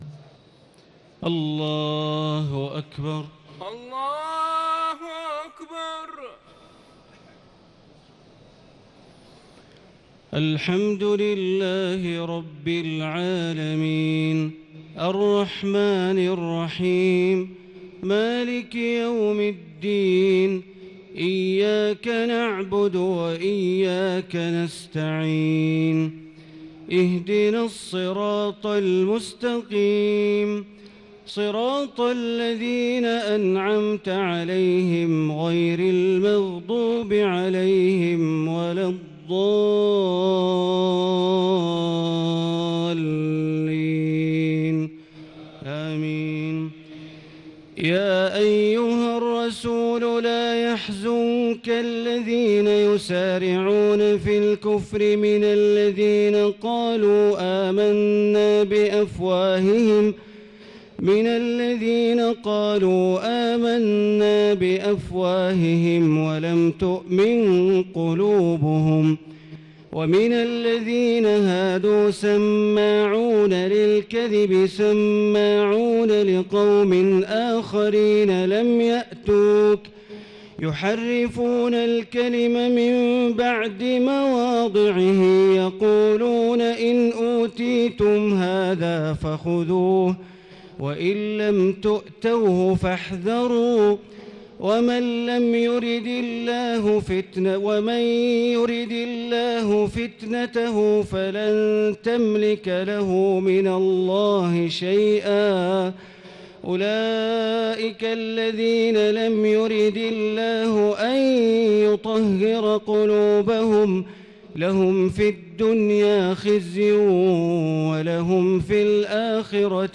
تهجد ليلة 26 رمضان 1439هـ من سورة المائدة (41-81) Tahajjud 26 st night Ramadan 1439H from Surah AlMa'idah > تراويح الحرم المكي عام 1439 🕋 > التراويح - تلاوات الحرمين